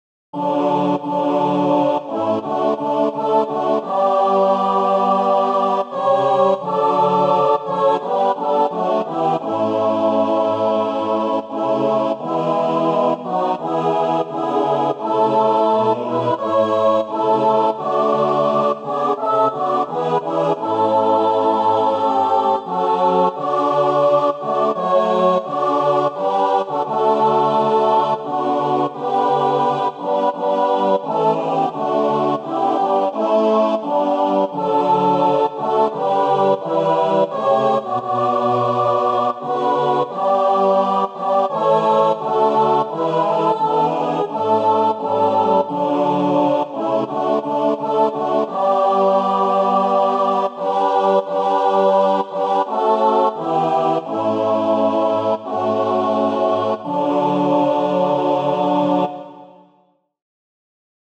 SATB, Youth Choir Mixed Or Unison
An original hymn. The first verse tells the story of Helaman's Stripling Warriors. The second verse focuses on us in our own time fighting against fear, hate and suffering.
Voicing/Instrumentation: SATB , Youth Choir Mixed Or Unison